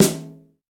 Shiny Snare D# Key 189.wav
Royality free steel snare drum sample tuned to the D# note. Loudest frequency: 2472Hz
shiny-snare-d-sharp-key-189-Sie.mp3